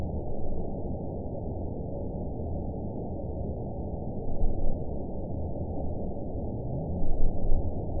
event 910679 date 01/29/22 time 01:02:37 GMT (2 years, 2 months ago) score 7.76 location TSS-AB07 detected by nrw target species NRW annotations +NRW Spectrogram: Frequency (kHz) vs. Time (s) audio not available .wav